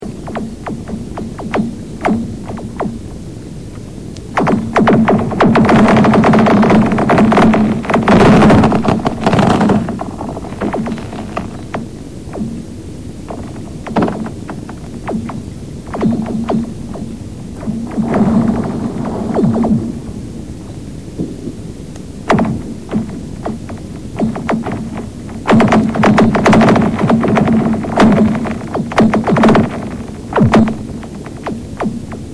Wenn unser See zugefroren ist und bei klarem Himmel am Tage die Sonne auf das blanke Eis strahlt beginnt dieses zu reißen.Dabei entstehen schöne und zugleich unheimliche Geräusche.Die habe ich aufgenommen.
Eisgesang.mp3